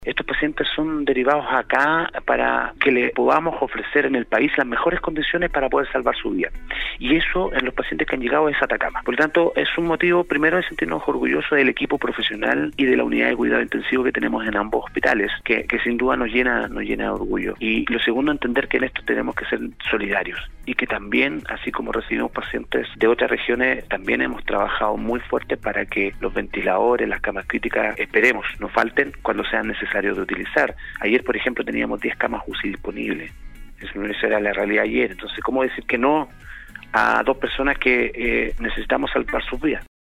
Vídeo: Claudio Baeza Avello, director servicio salud Atacama entregó un balance regional de la emergencia sanitaria
En el siguiente enlace puede ver y conocer todos los detalles de esta entrevista realizada en el programa Al Día de Nostálgica: